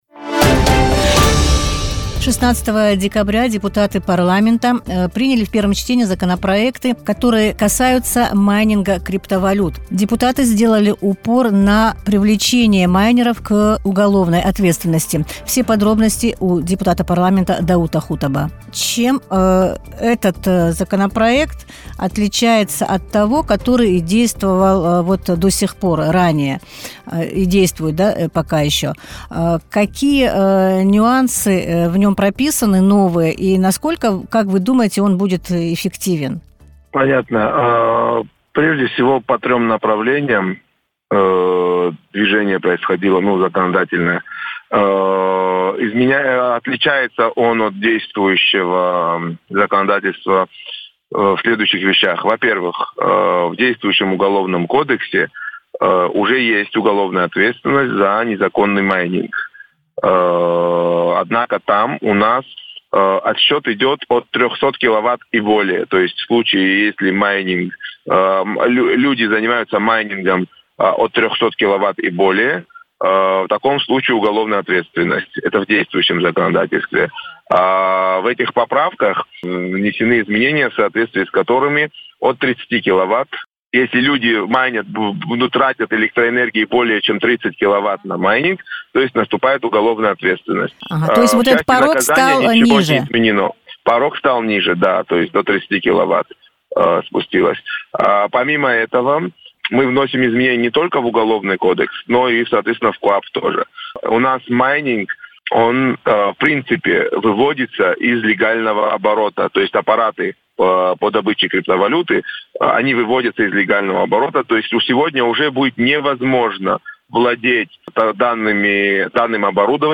Депутат Парламента Абхазии Даут Хутаба в эфире радио Sputnik прокомментировал работу над проектами закона, ужесточающими наказание за майнинг криптовалюты.